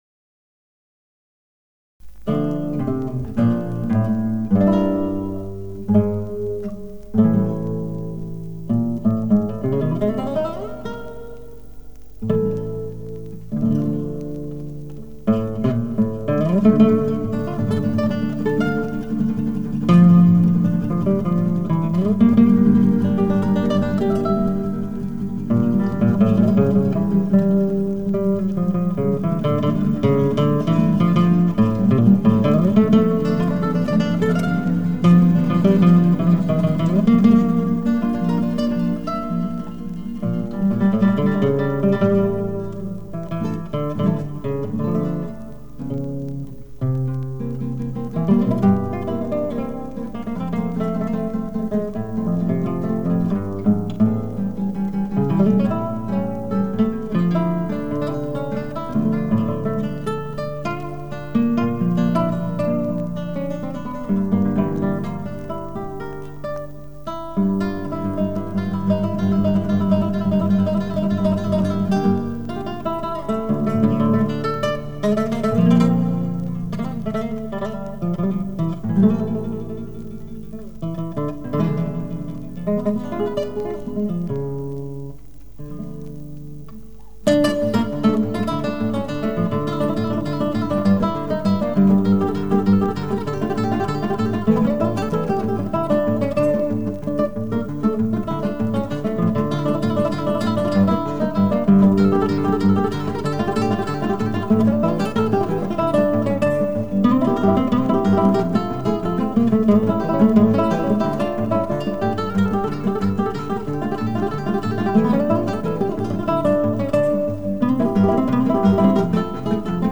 великолепный гитарист. К сожалению, это шумы с пластинки.